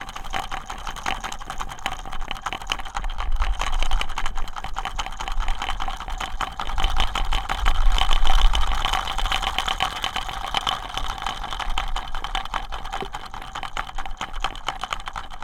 바람개비.mp3